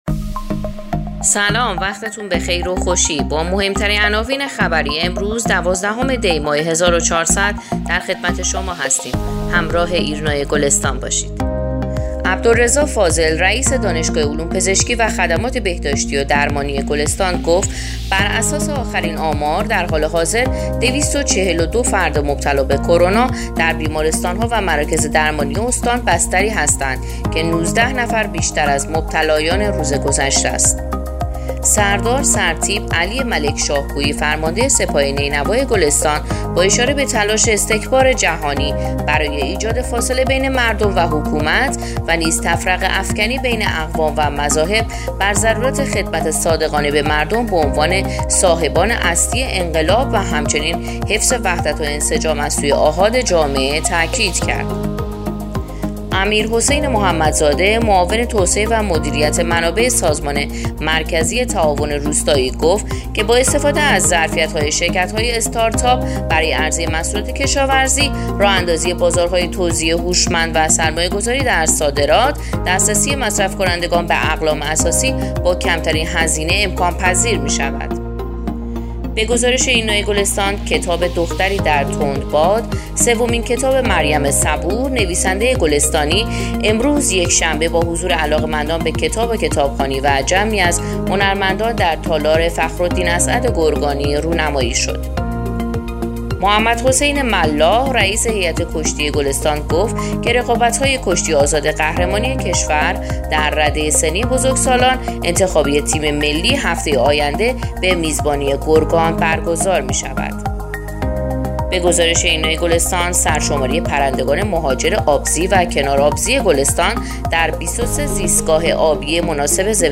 پادکست/ اخبار شبانگاهی دوازدهم دی ماه ایرنا گلستان